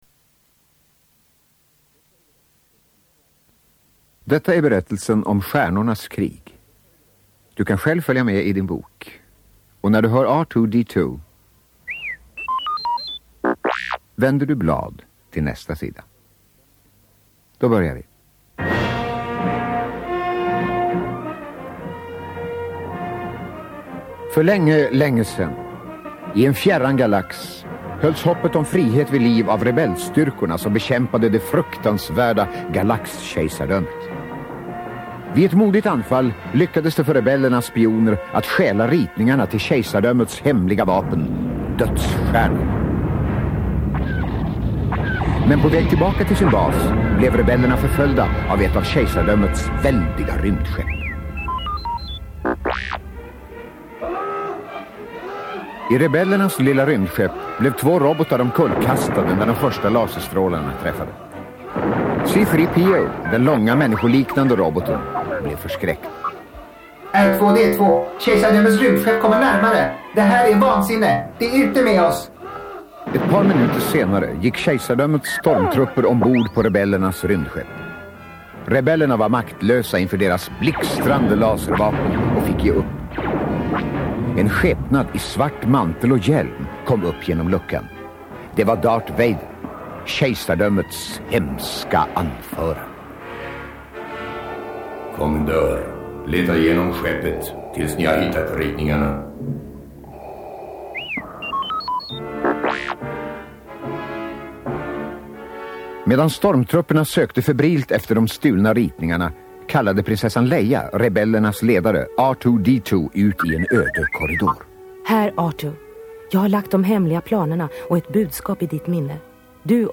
Hoojiberna better quality (Flux) Back to startpage Vintage Saga Tapes Swedish These are the vintage tapes with the Star Wars Saga in Swedish!
Audio-tape + booklet!
Ljudbok_EpisodeIV.mp3